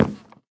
sounds / step / wood1.ogg
wood1.ogg